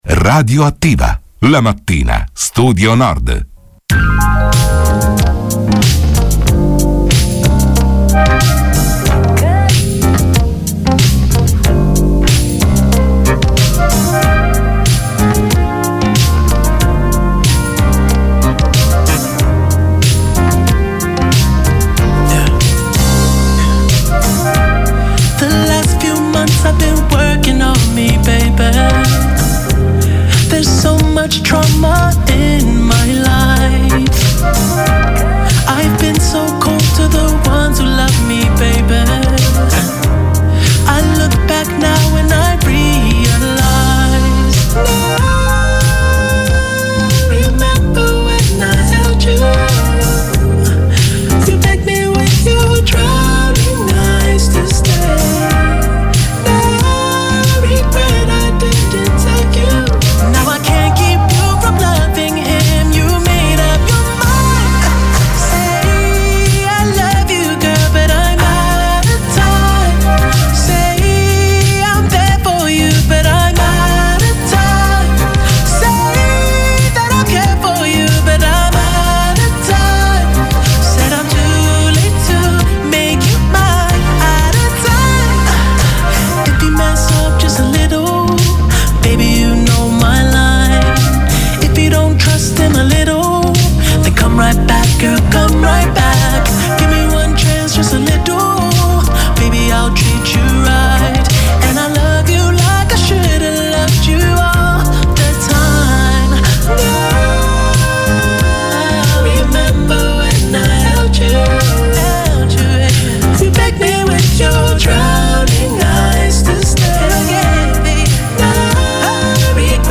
Dell’iniziativa si è parlato a “RadioAttiva“, la trasmissione di Radio Studio Nord